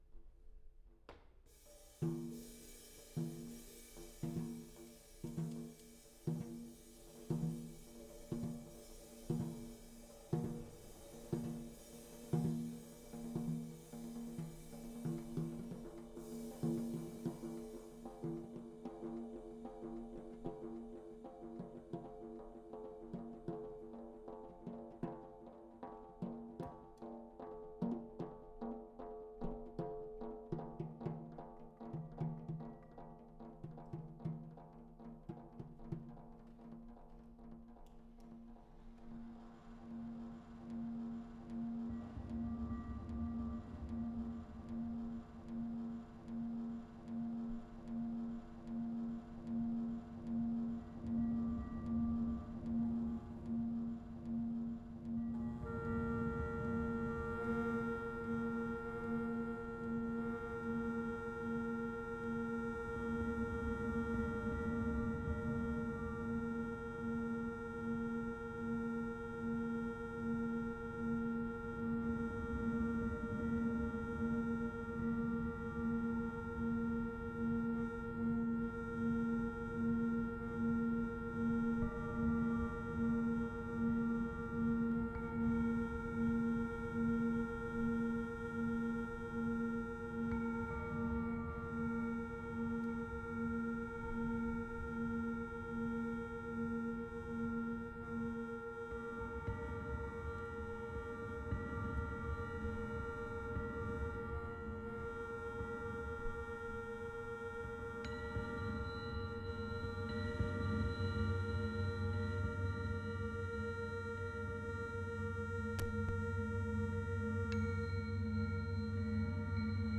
A new and unique improvising trio of master musicians
drums
guitars
bagpipes